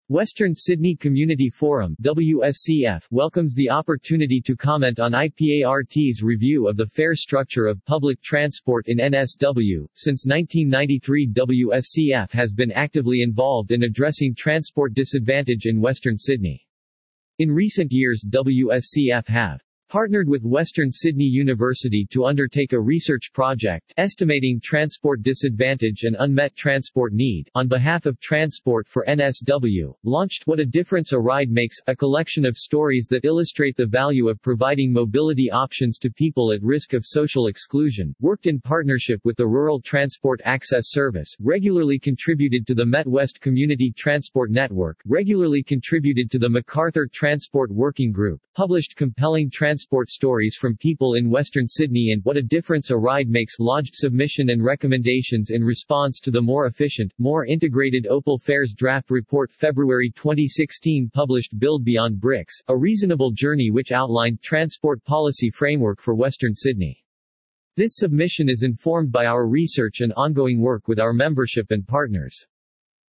Text to speech